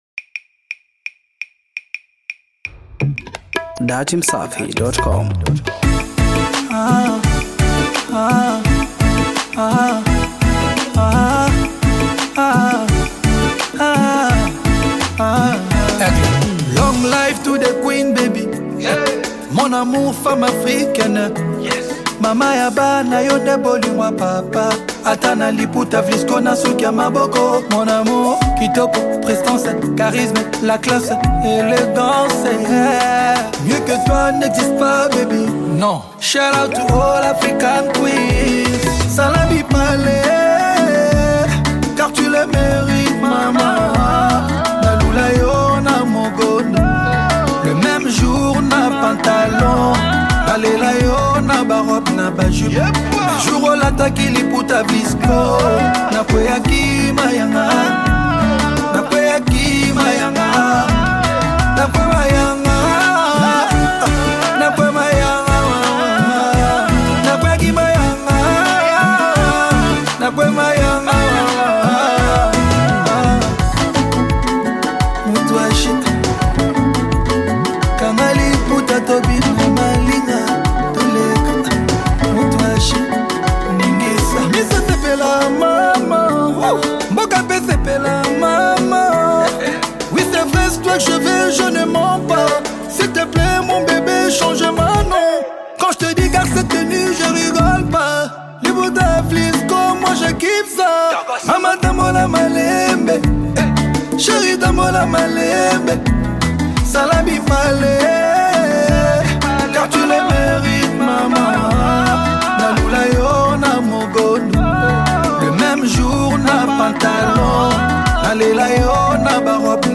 Congo Music